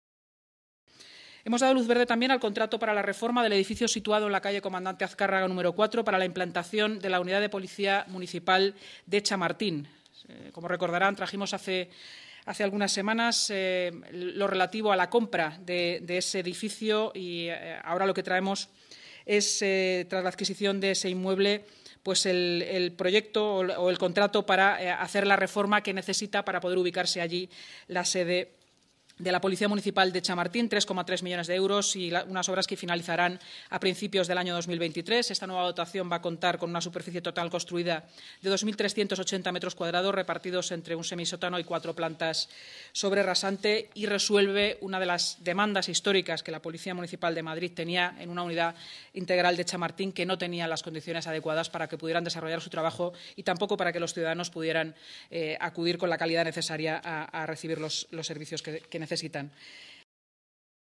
Nueva ventana:Declaraciones de Inmaculada Sanz, portavoz municipal y delegada de Seguridad y Emergencias, durante la rueda de prensa posterior a la Junta de Gobierno